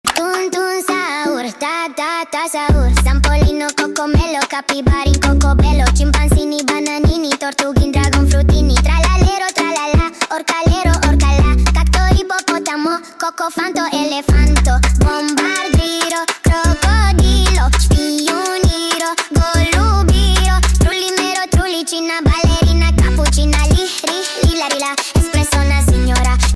Crazy Singing Luck Again 😭 sound effects free download